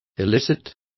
Complete with pronunciation of the translation of illicit.